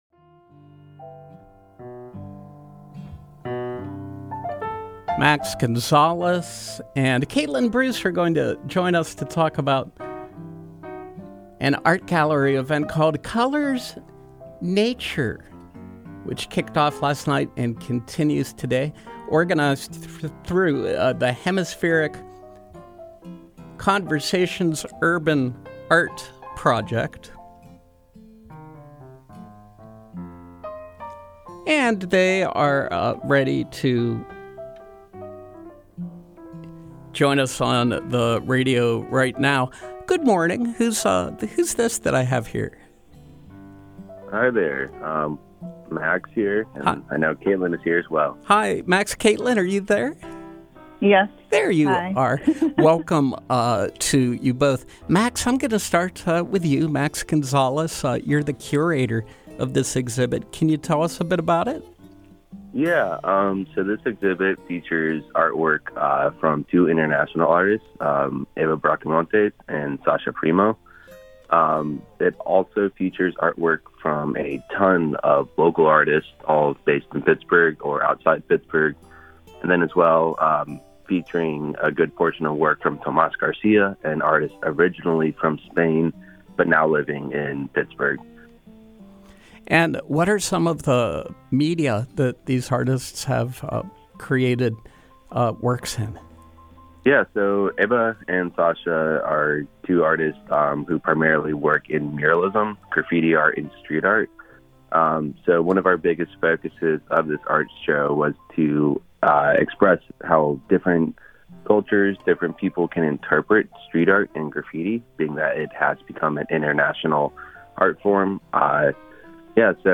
Home » Featured, Interviews